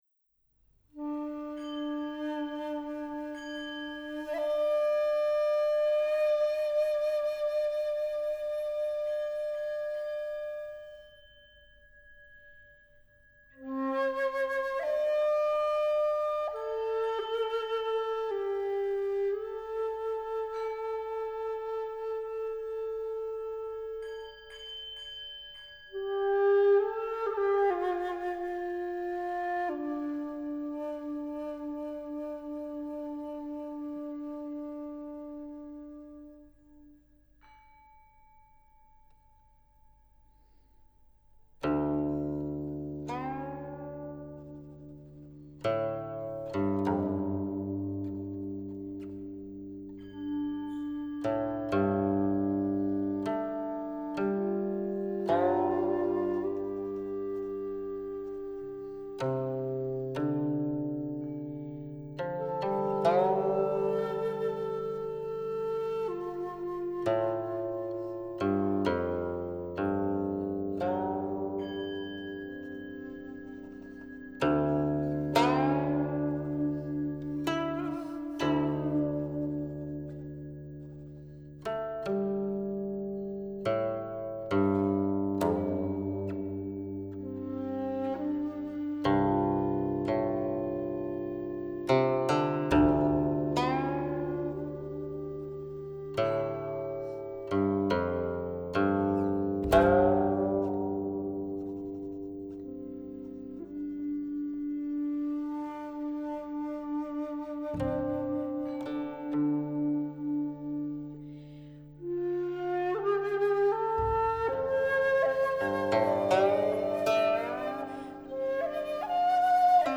★ 技藝超群的七仙女以音符描繪大唐盛景！